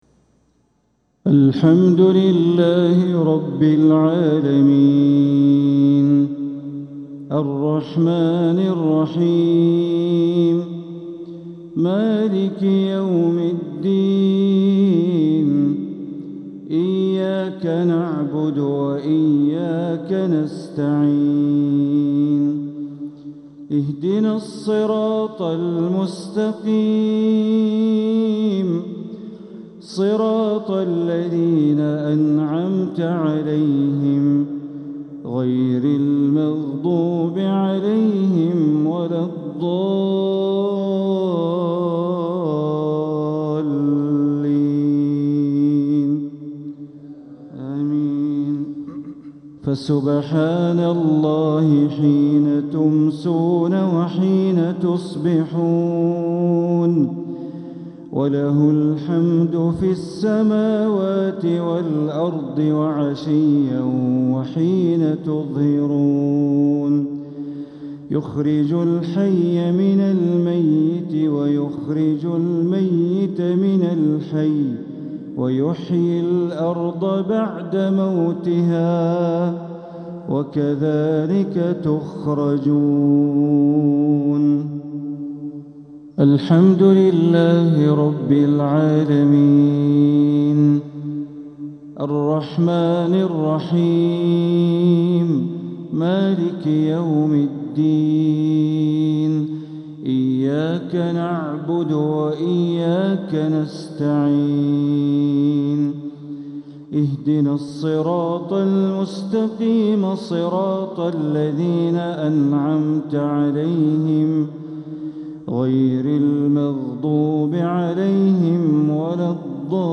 تلاوة من سورتي الروم ويس مغرب الأحد ١ ربيع الأول ١٤٤٧ > 1447هـ > الفروض - تلاوات بندر بليلة